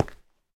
sounds / step / stone4.ogg
stone4.ogg